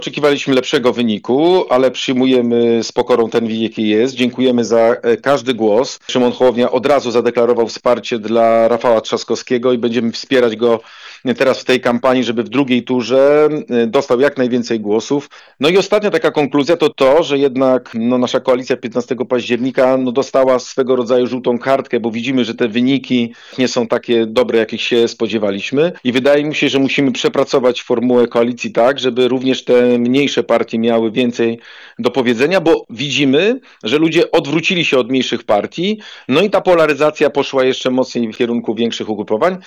Do wyniku głosowania odniósł się wojewoda zachodniopomorski Adam Rudawski – przedstawiciel Polski 2050. W rozmowie z Twoim Radiem przyznał otwarcie: